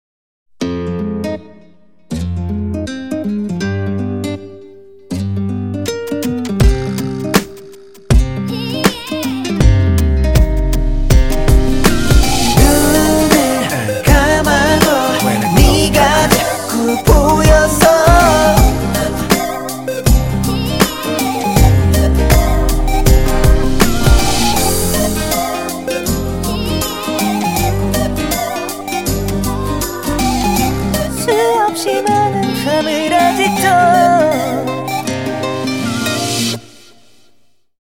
karanlık ve büyüleyici bir melodiye sahip